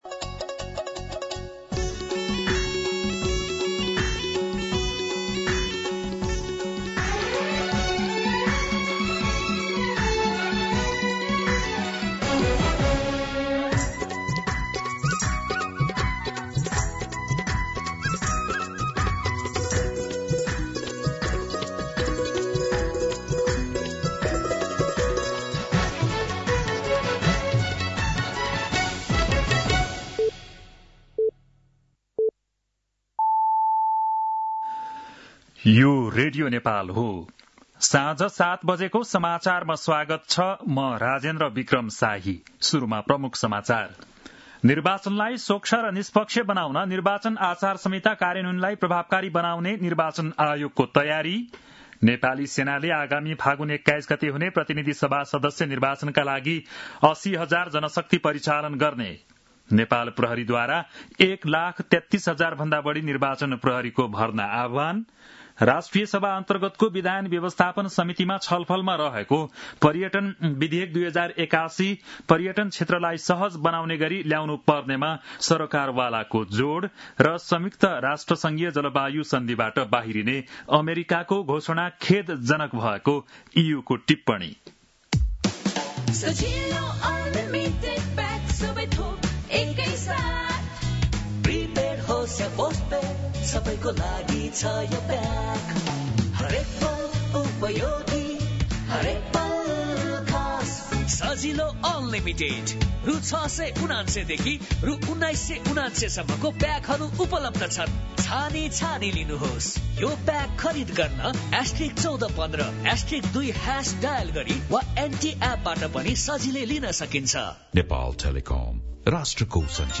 बेलुकी ७ बजेको नेपाली समाचार : २४ पुष , २०८२
7-pm-nepali-news-9-24.mp3